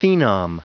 Prononciation du mot phenom en anglais (fichier audio)
Prononciation du mot : phenom